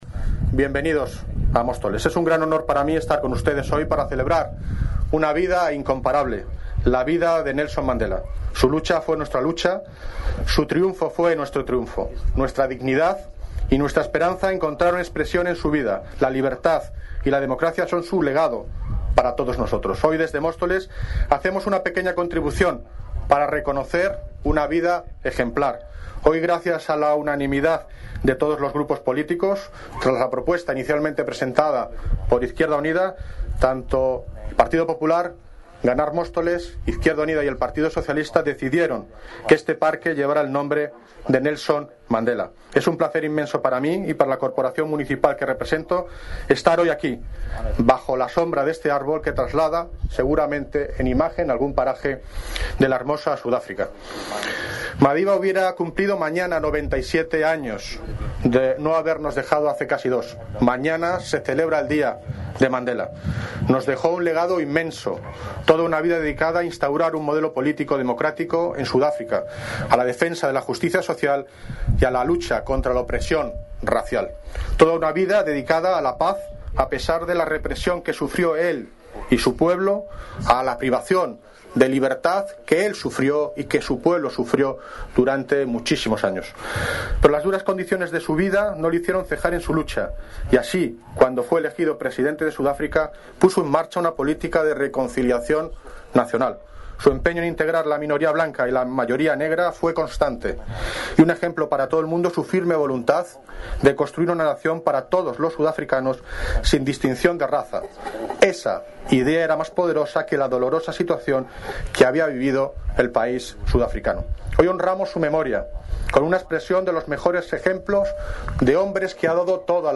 Audio - David Lucas (Alcalde de Móstoles) Sobre discurso parque Nelson Mandela